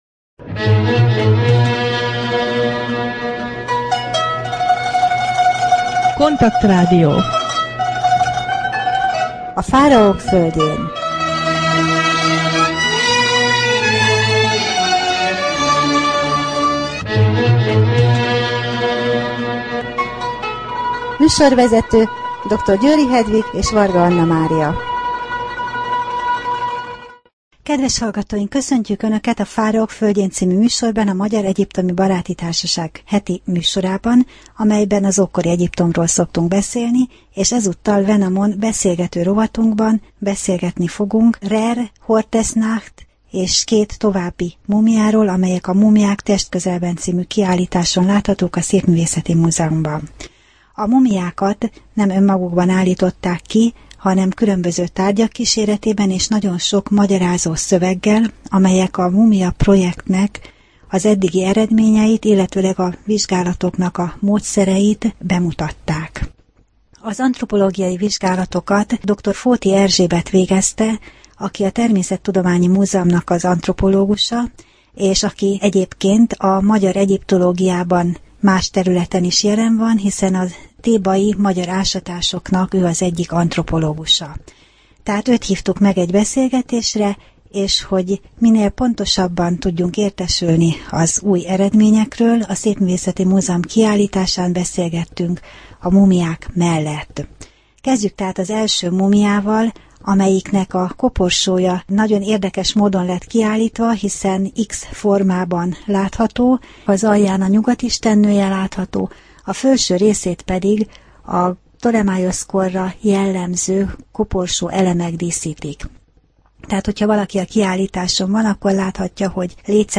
Rádió: Fáraók földjén Adás dátuma: 2011, August 26 Fáraók földjén Wenamon beszélgető rovat / KONTAKT Rádió (87,6 MHz) 2011 augusztus 26.